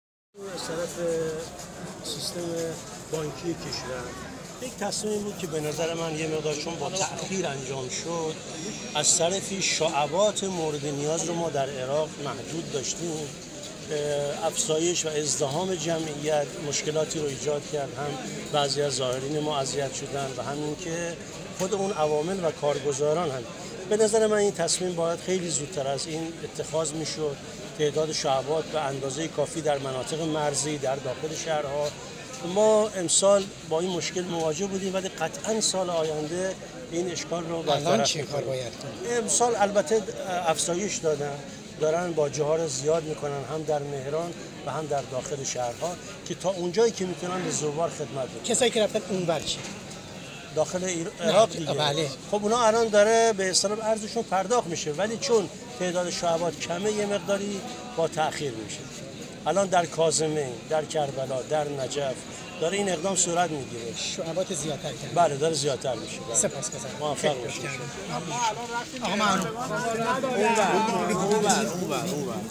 به گزارش خبرنگار مهر، ایرج مسجدی ظهر چهارشنبه در بازدید از پایانه مرزی مهران اظهار داشت: شعبه های مورد نیاز برای اختصاص ارز در عراق و مناطق مرزی یکی از مشکلات بر سر راه ارائه ارز است.